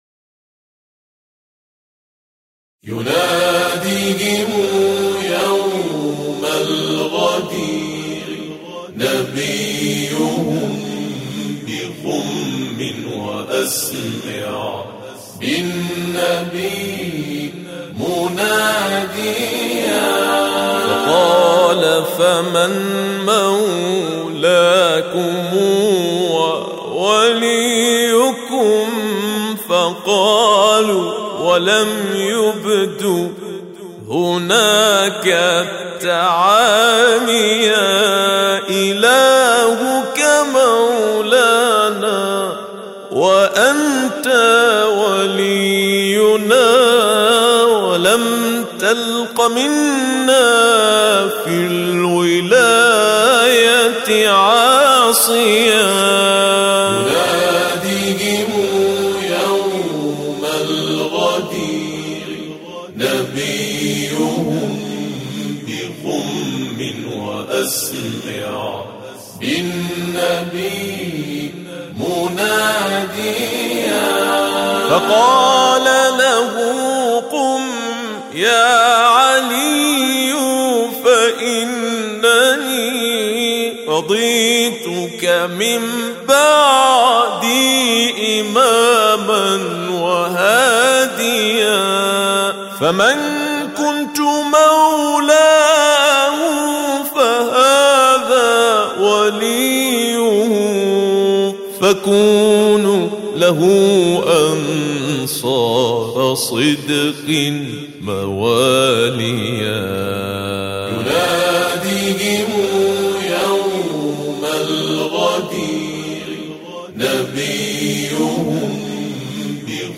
أنشودة: يناديهم يوم الغدير نبيهم